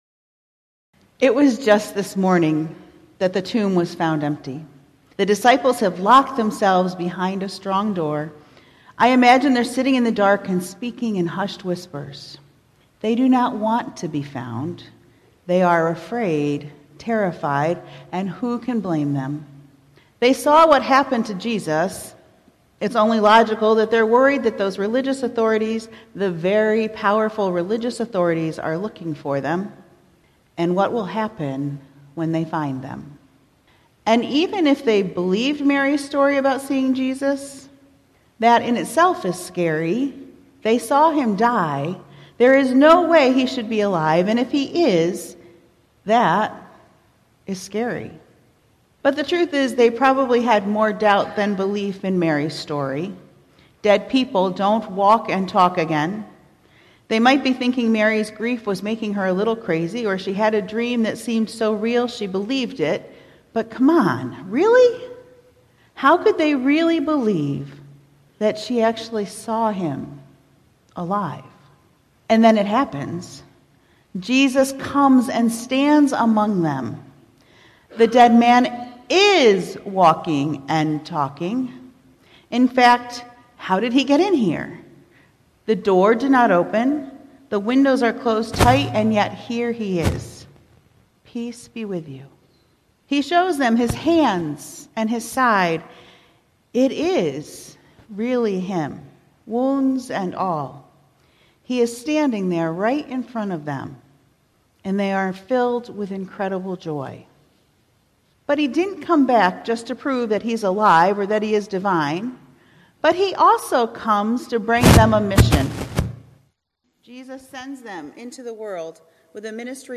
April 8, 2018 Sermon, "A Story to Tell" • Nardin Park Church